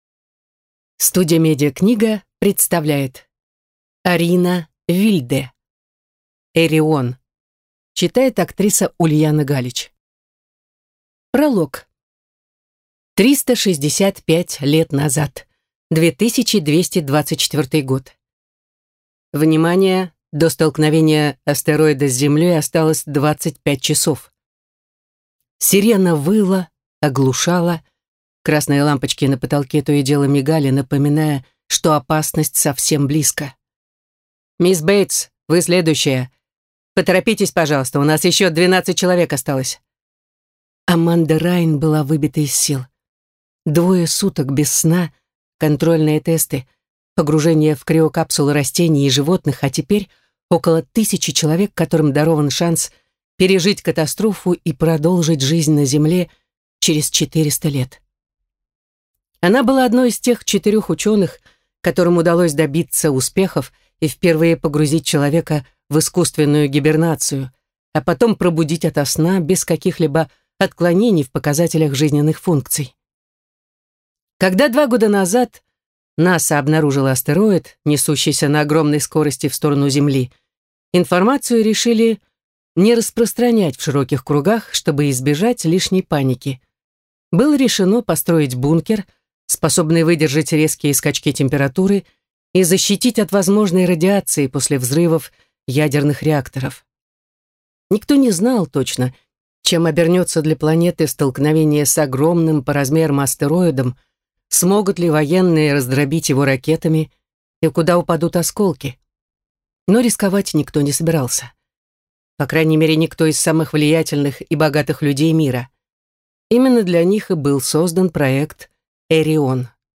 Аудиокнига Эрион | Библиотека аудиокниг
Прослушать и бесплатно скачать фрагмент аудиокниги